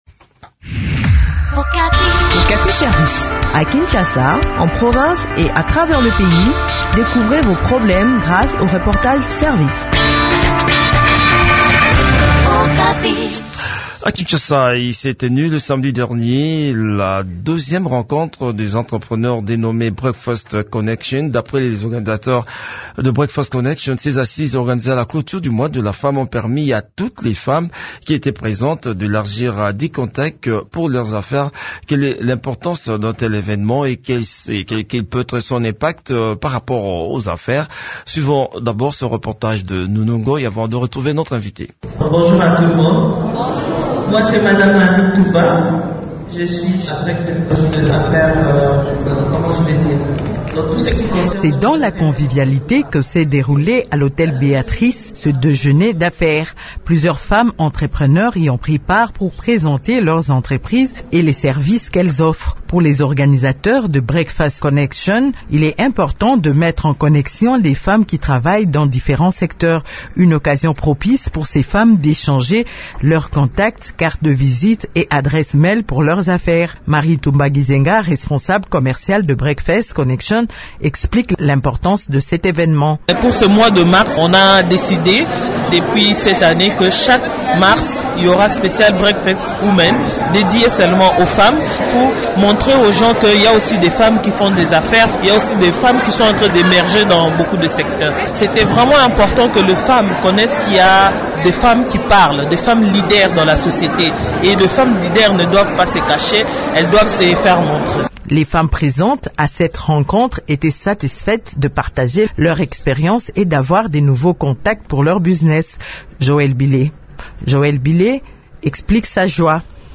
Le point sur le déroulement de cette rencontre dans cet entretien